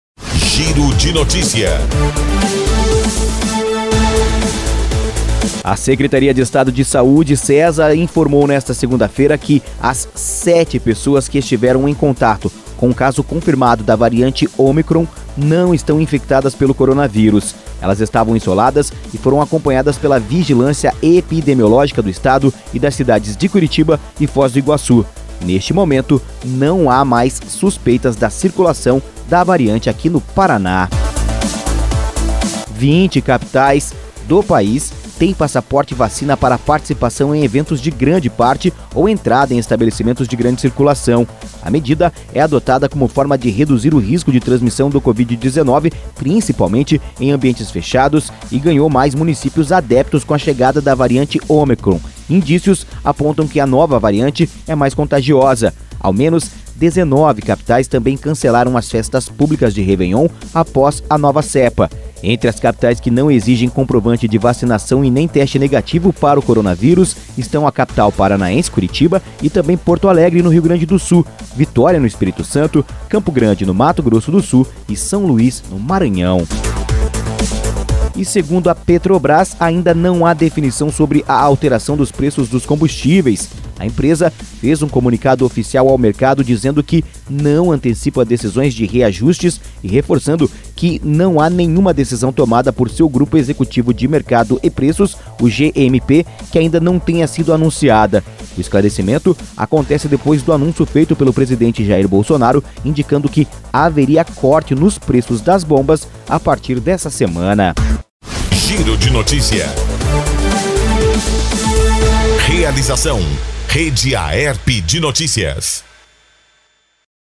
Giro de Notícias – Manhã